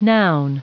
Prononciation du mot noun en anglais (fichier audio)
Vous êtes ici : Cours d'anglais > Outils | Audio/Vidéo > Lire un mot à haute voix > Lire le mot noun